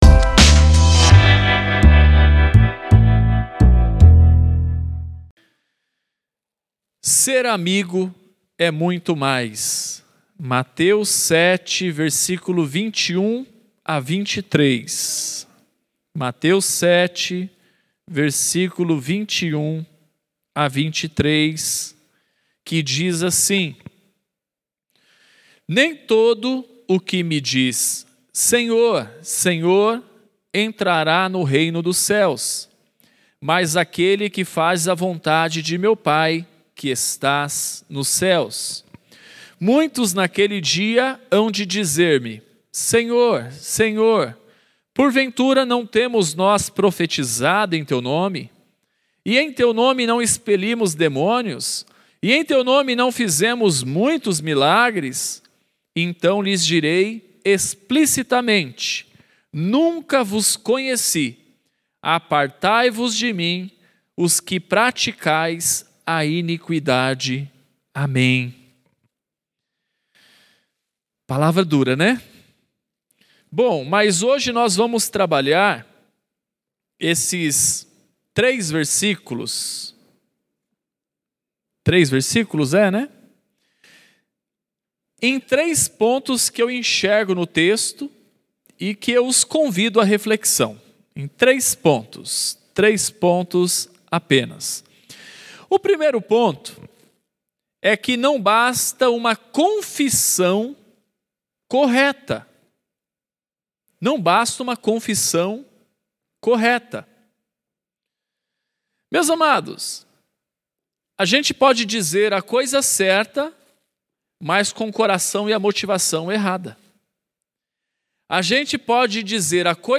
Mensagem realizada no Culto de Reflexão de Oração.